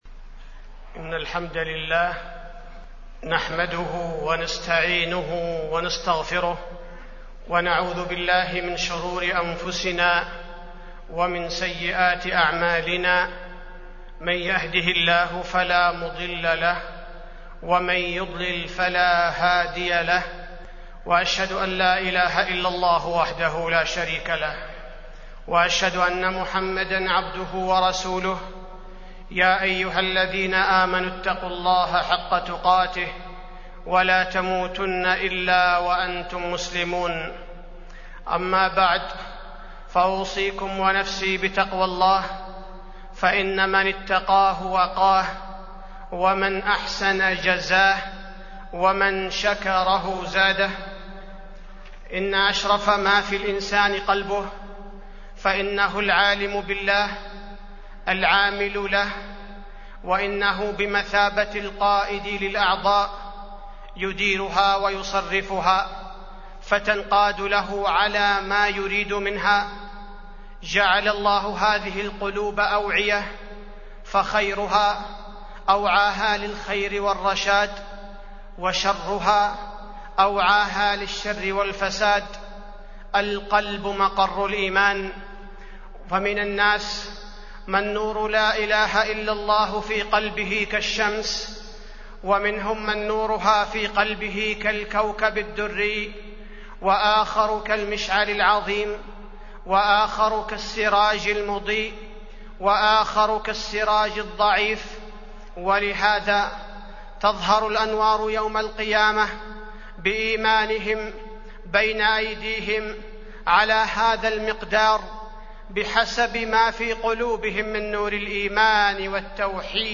تاريخ النشر ١٥ جمادى الأولى ١٤٢٨ هـ المكان: المسجد النبوي الشيخ: فضيلة الشيخ عبدالباري الثبيتي فضيلة الشيخ عبدالباري الثبيتي أصحاب القلوب الثلاثة